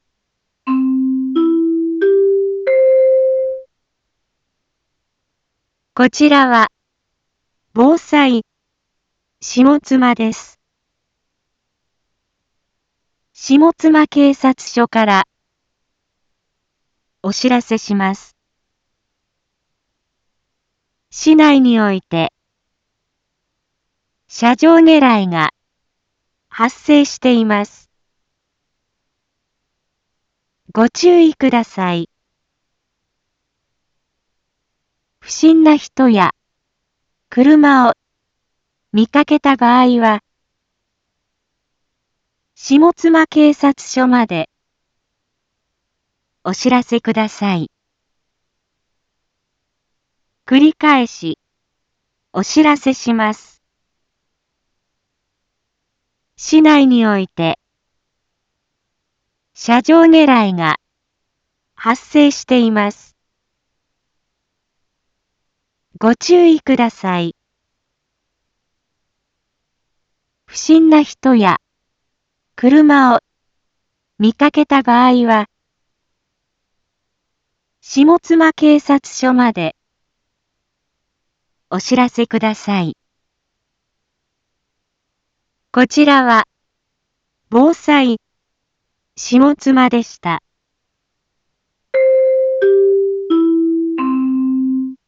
一般放送情報
Back Home 一般放送情報 音声放送 再生 一般放送情報 登録日時：2021-04-29 12:31:39 タイトル：車上ねらいへの警戒について インフォメーション：こちらは、防災下妻です。